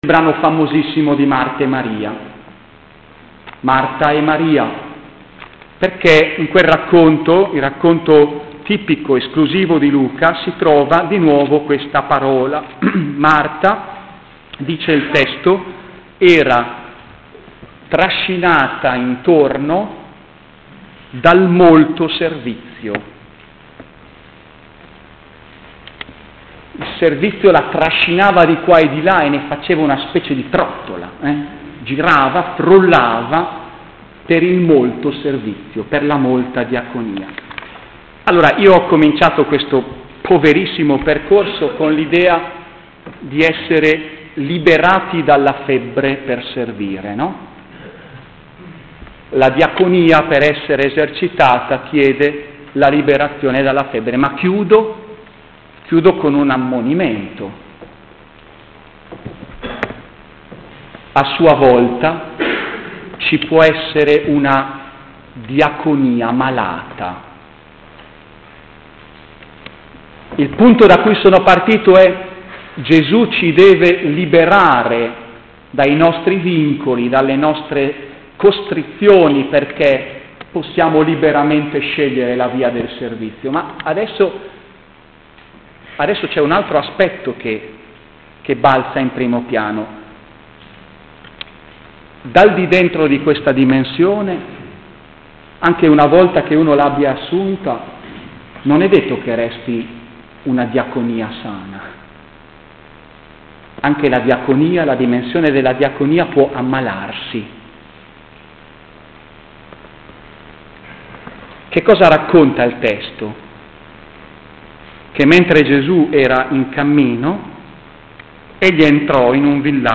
Tratto da un incontro sulla diaconia (servizio)